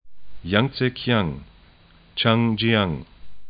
'jaŋtsəkĭaŋ